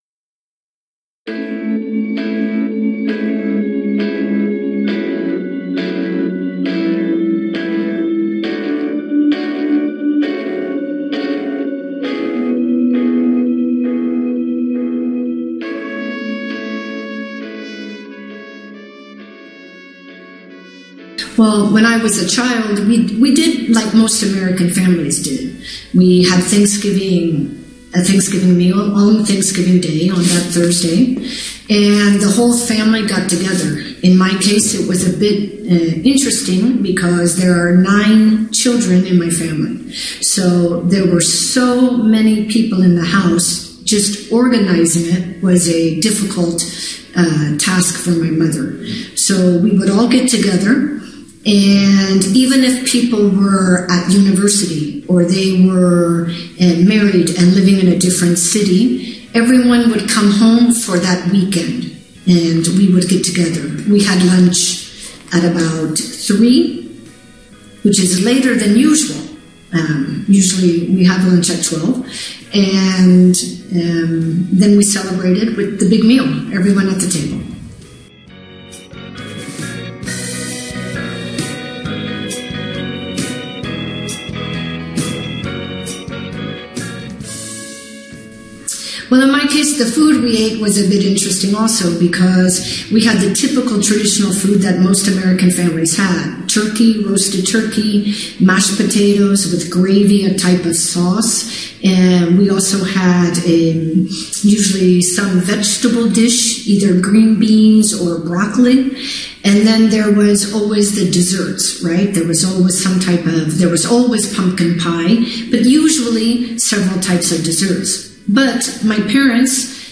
THANKSGIVING - THE INTERVIEW
AUDIOTHANKSGIVINGTHEINTERVIEW.mp3